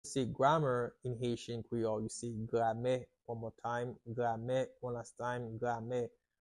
How to say "Grammar" in Haitian Creole - "Gramè" pronunciation by a native Haitian Creole tutor
“Gramè” Pronunciation in Haitian Creole by a native Haitian can be heard in the audio here or in the video below:
How-to-say-Grammar-in-Haitian-Creole-Grame-pronunciation-by-a-native-Haitian-Creole-tutor.mp3